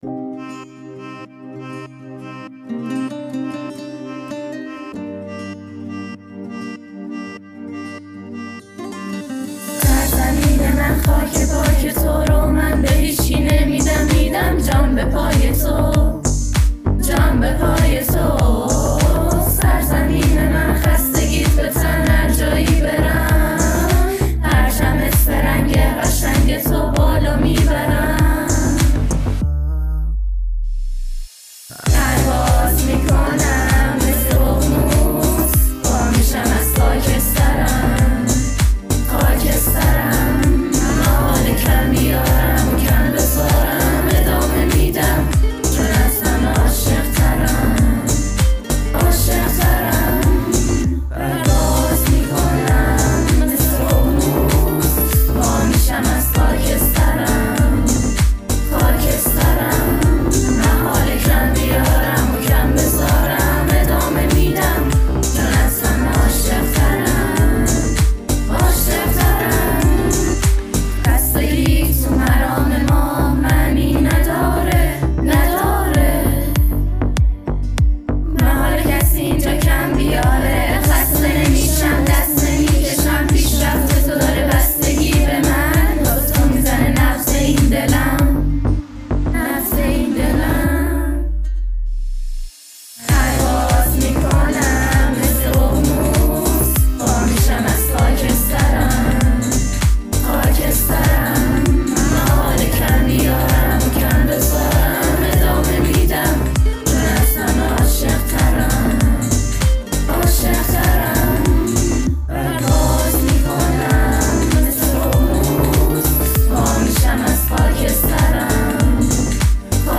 حماسی و ارزشی